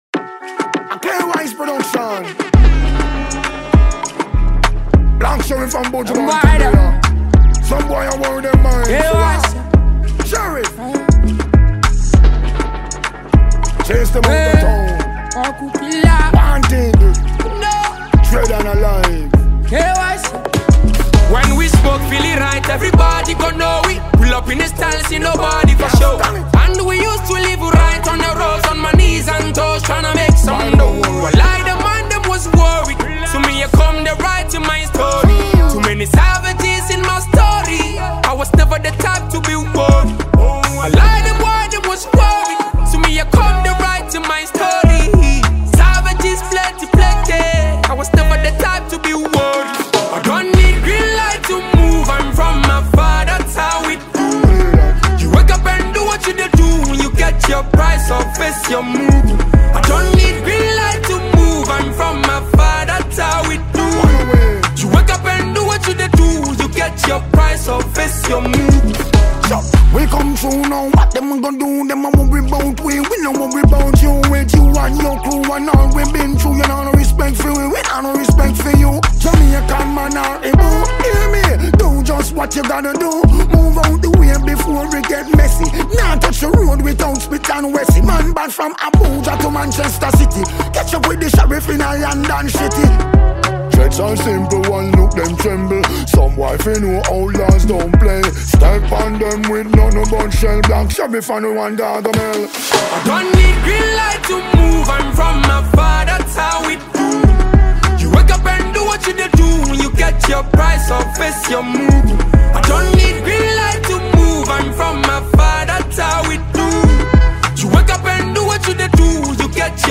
This track blend with reggae and Afrobeat.